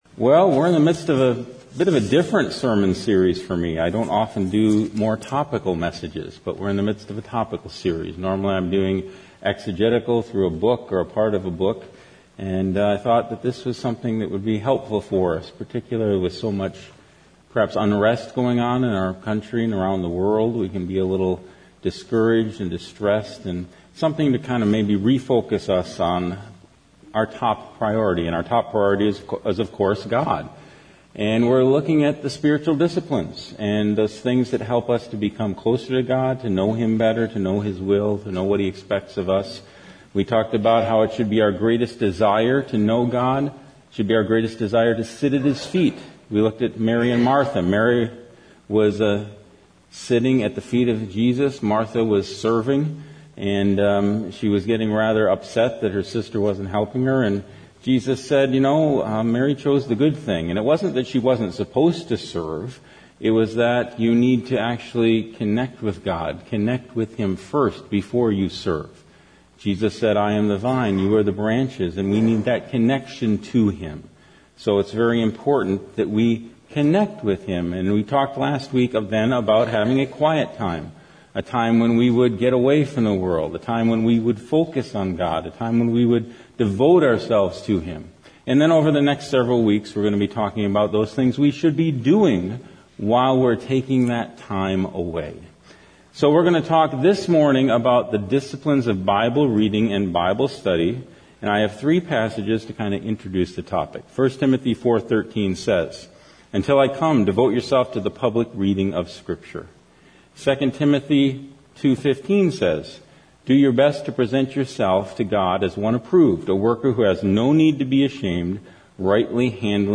First Baptist Church Sermons